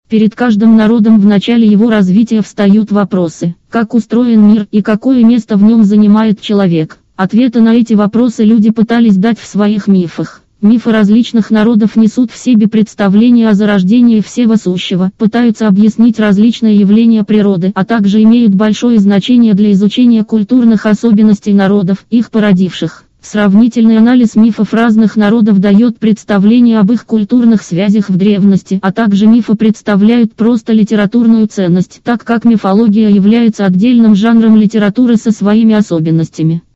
RHVoice - это SAPI5-совместимый синтезатор русской речи.
Синтезатор обладает двумя разборчивыми и выразительными голосами: мужским Александр и женским Елена, которые отличаются высокой скоростью работы.
Скачать демонстрационный файл женского голоса Елена [217 kB]
rhvoice_elena.mp3